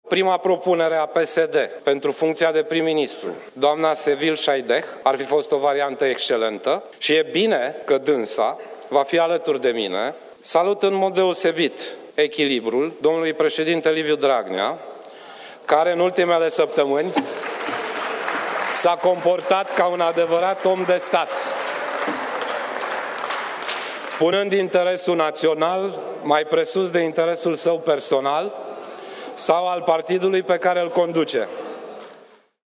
Premierul desemnat, Sorin Grindeanu, şi-a început discursul din plenul reunit al Parlamentului în care cere votul de încredere mulţumind PSD pentru propunere şi afirmând că Sevil Shhaideh ar fi fost o variantă “excelentă” de premier.